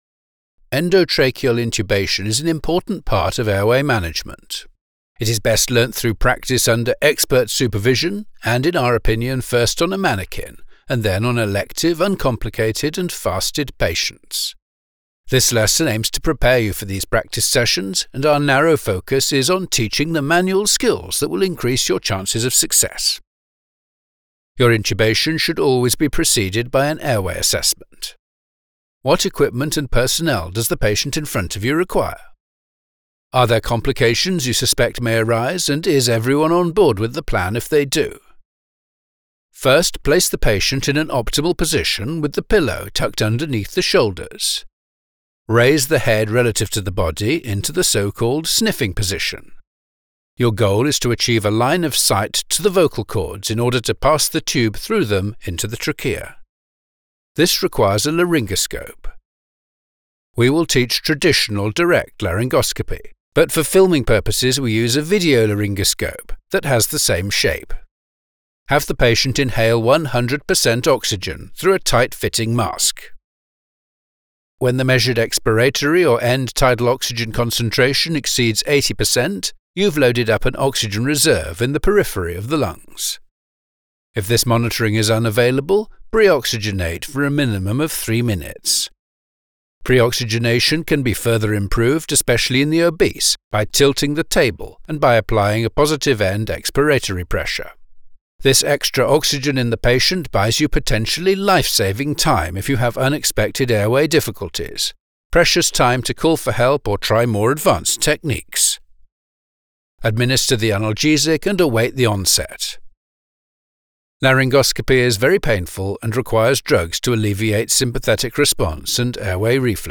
British Voiceover for Medical Narration: